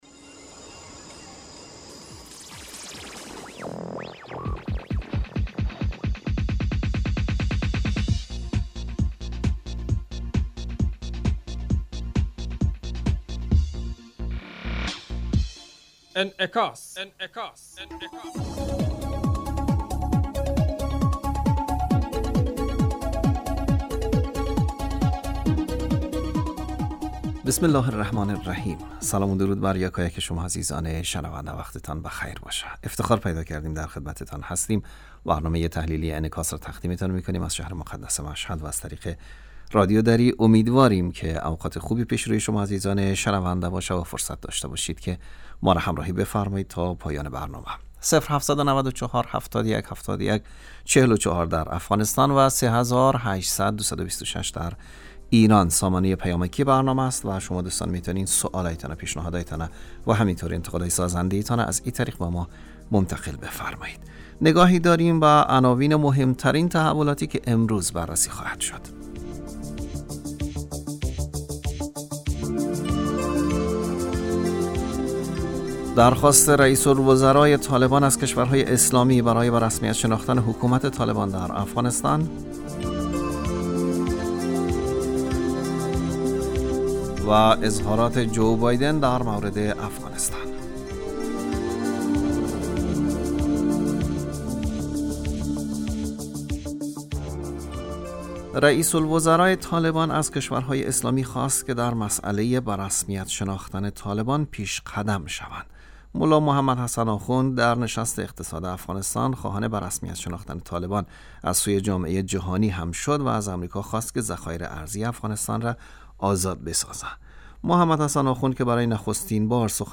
برنامه انعکاس به مدت 30 دقیقه هر روز در ساعت 12:15 ظهر (به وقت افغانستان) بصورت زنده پخش می شود.